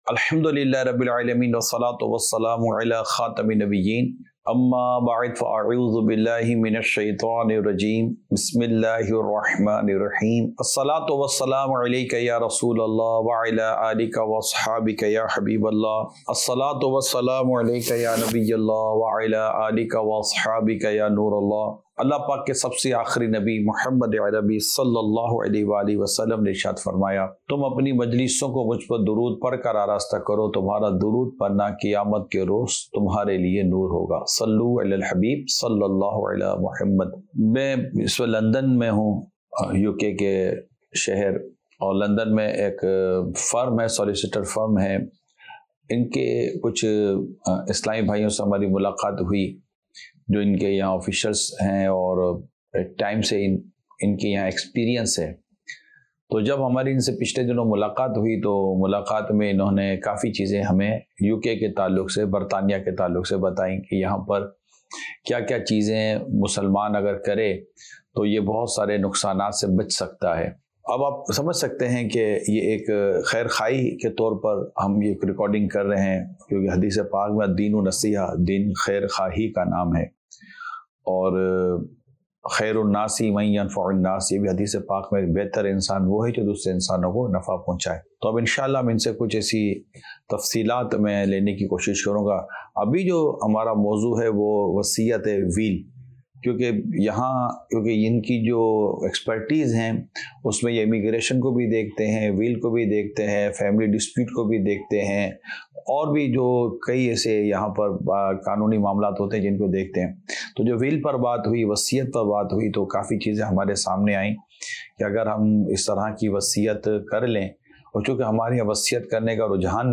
Sunnah Inspired Bayan - A Will